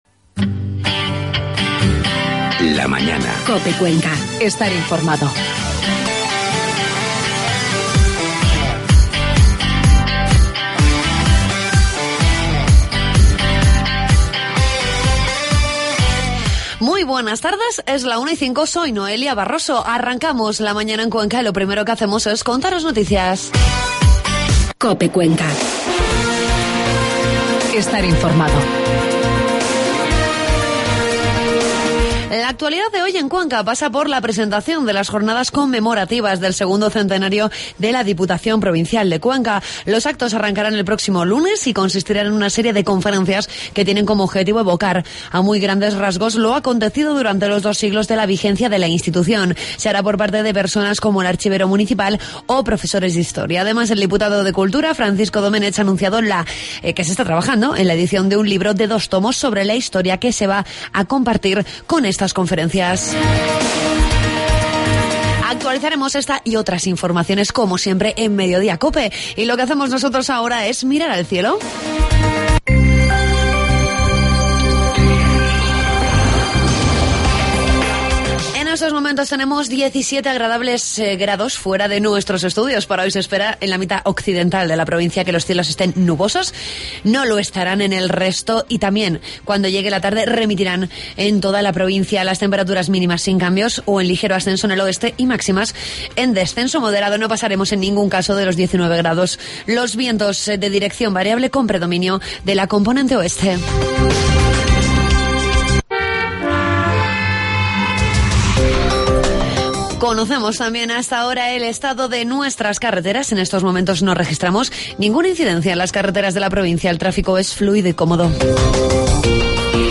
Hablamos con el diputado de Turismo, Francisco Domenech, con el conocemos el ciclo de conferencias que va a poner en marcha la Institución Provincial con motivo del bicentenario.